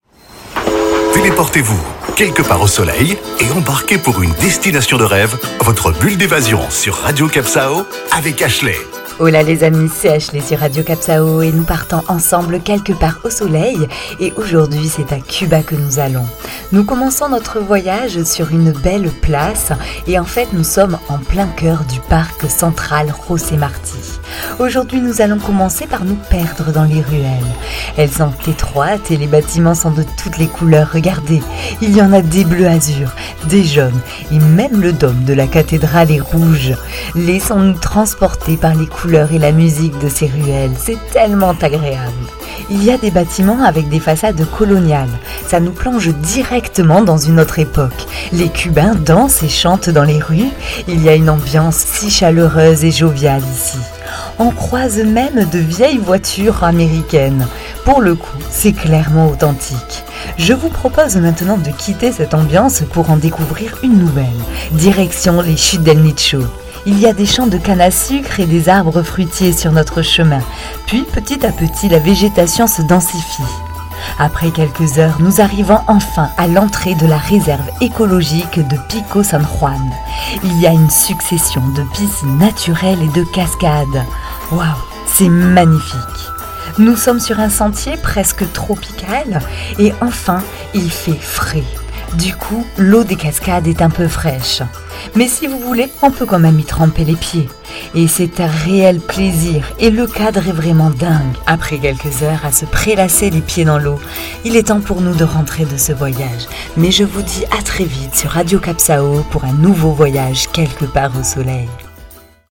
Carte postale sonore : avant de partir explorer les piscines naturelles et la jungle tropicale de la réserve del Nicho, arpentez les rues de Cienfuegos et le parc José Marti, dans une ambiance chaleureuse, dansante et joviale avec les cubains.